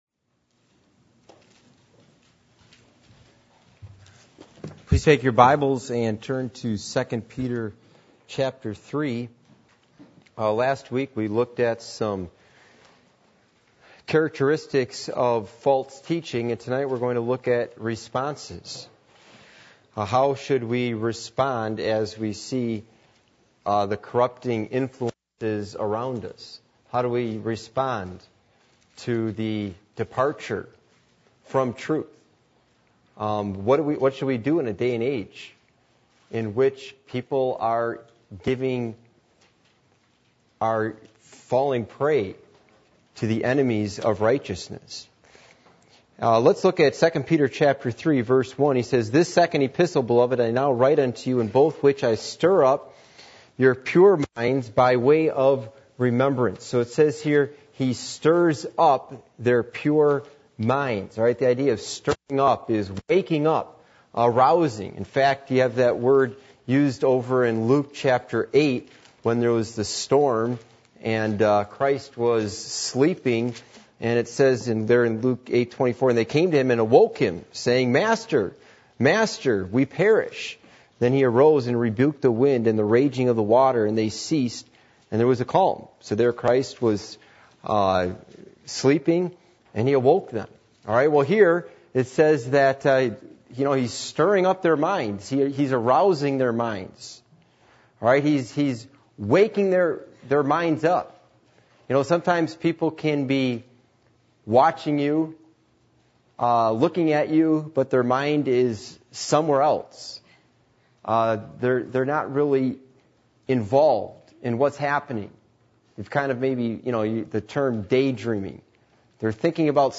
Passage: 2 Peter 3:1-1:9, 2 Peter 3:16-18 Service Type: Midweek Meeting